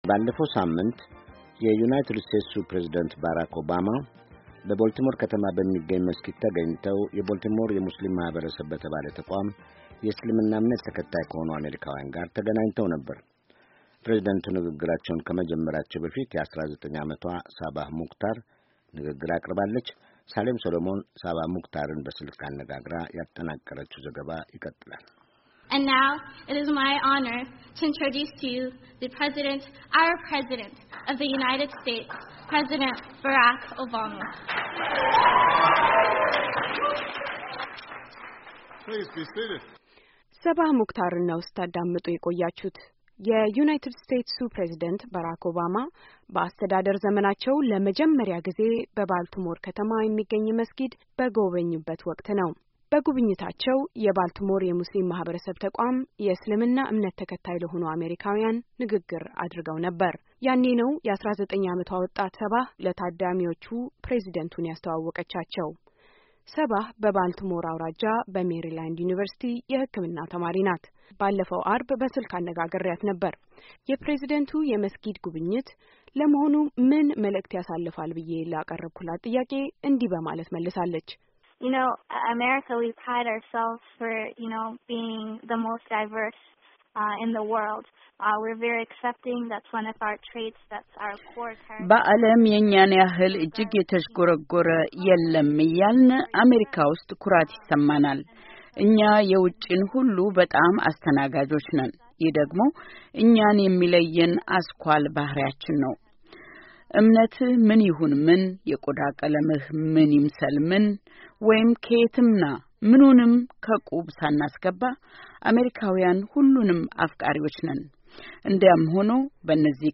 በስልክ አነጋግራ ያጠናቀረችው ዘገባ አለ።